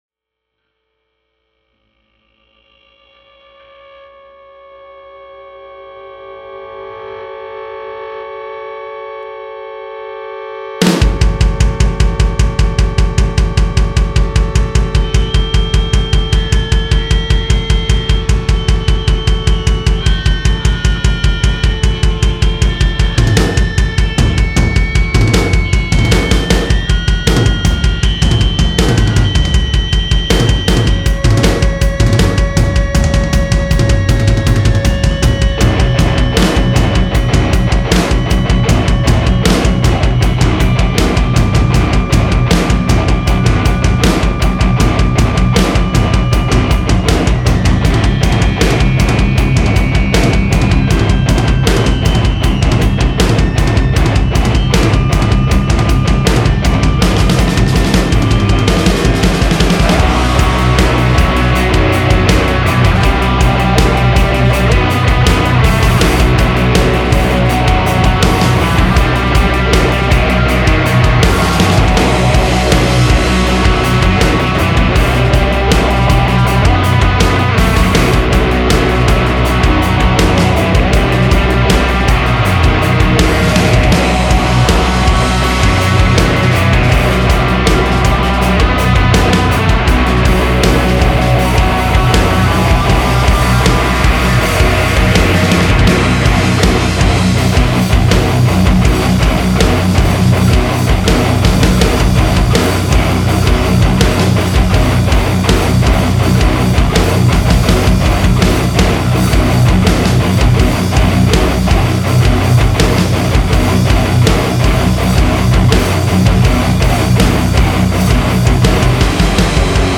Recorded On Analog Tape At Electrical Audio
Full Mix